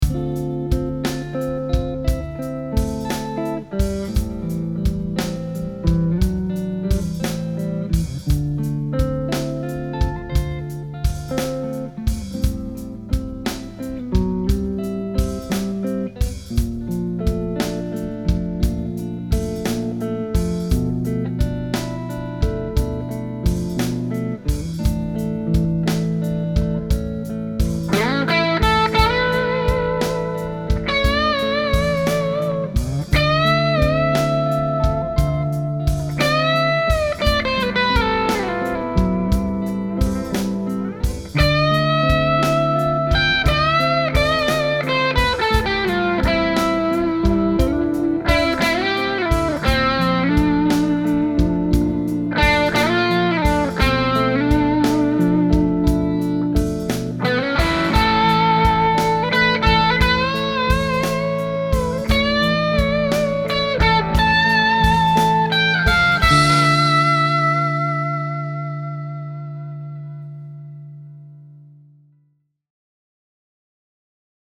The cleans were sparkly with a nice, substantial bottom end, and the overdrive tones with my ’59 Les Paul replica got me quickly into Rock and Roll nirvana.
Both clips were recorded with my Aracom VRX22 and my ’59 Les Paul replica plugged straight into the amp. I only added a touch of small room reverb in my DAW to give the tone a bit of grease:
Clean with a Dirty Lead